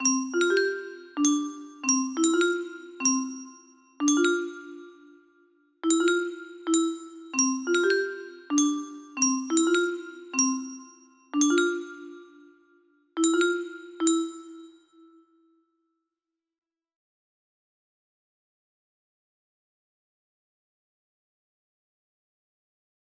Random Melody 1 music box melody